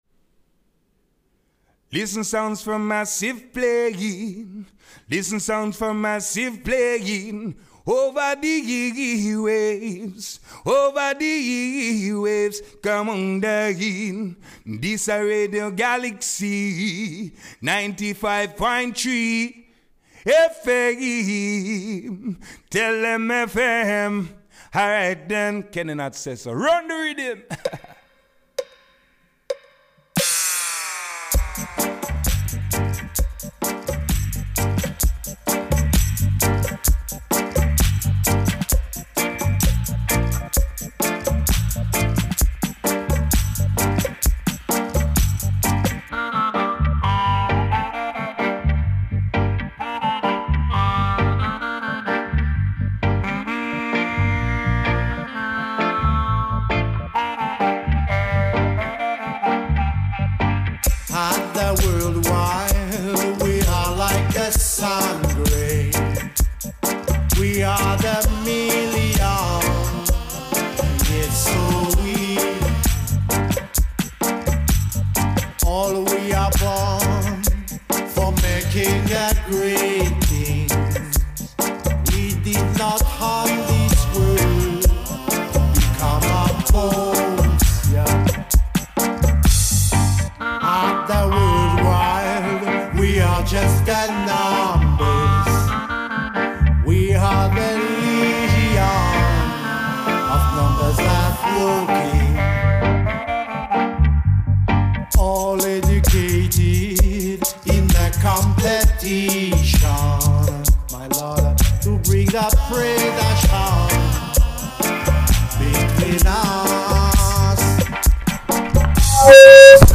beaucoup de cuivres